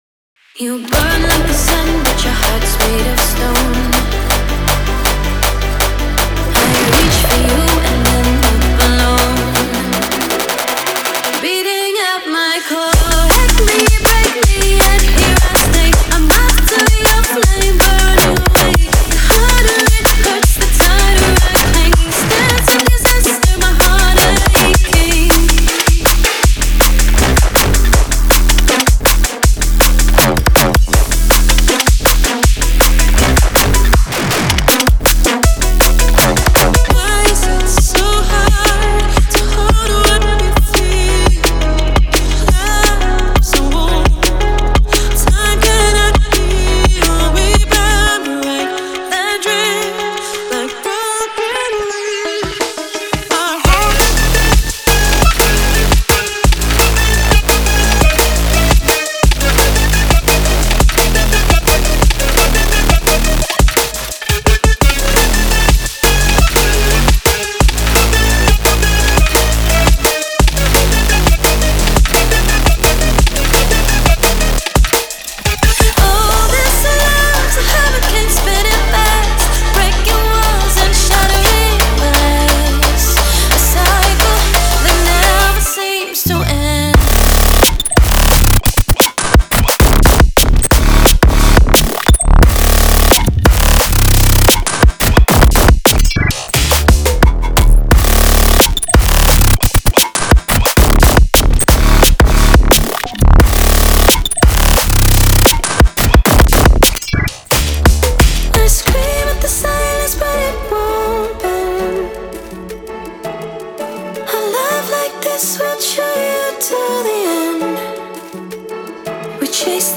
デモサウンドはコチラ↓
Genre:Drum and Bass
160, 165, 172, 175 BPM
16 Vocal Loops (8 Dry, 8 Wet)